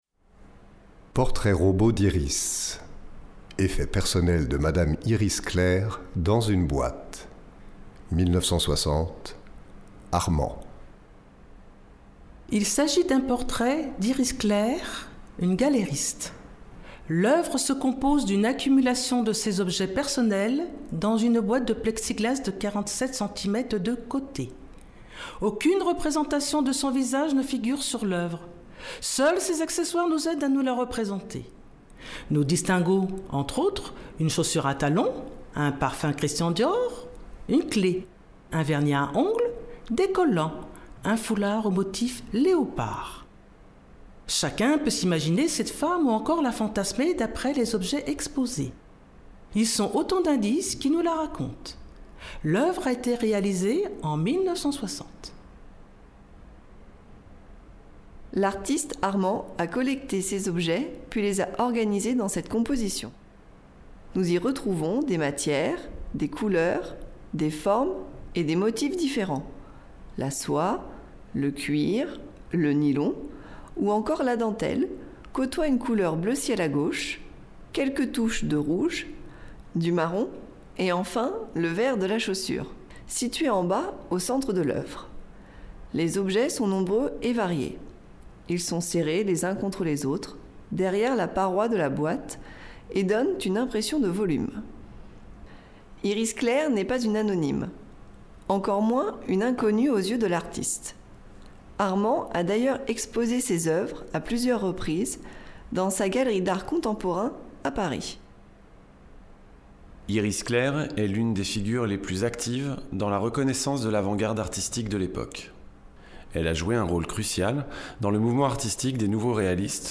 Prêtez votre voix à la lecture d’une œuvre d’art pour des personnes malvoyantes – Mars 2018
Une douzaine de collaborateurs ont prêté leur voix à la lecture de quatre nouvelles oeuvres d’art afin de les rendre accessibles à un public malvoyant.